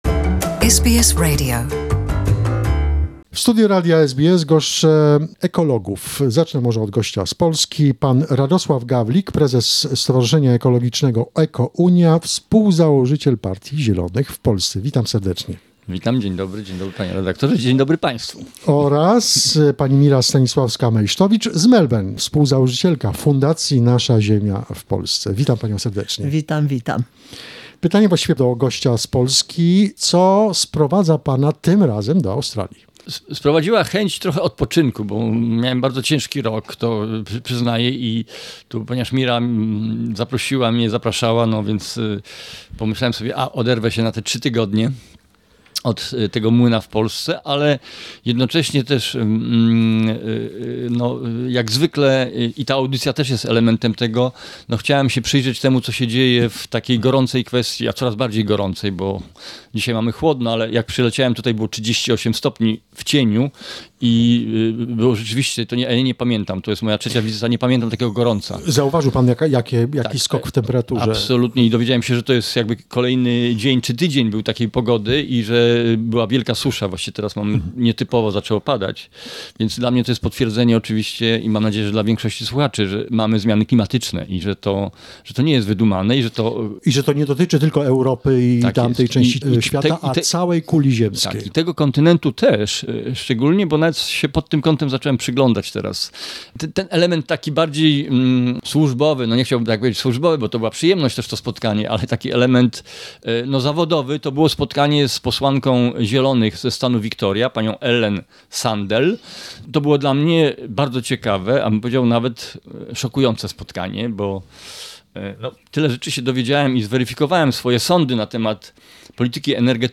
Radoslaw Gawlik, ecologist, politician, co-founder of the Green party in Poland, president of the Ecological Association EKO-UNIA talks about how Australia and Poland are performing in achieving the Sustainable Development Goals. Also joined us in the studio